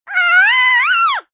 babyscream4.ogg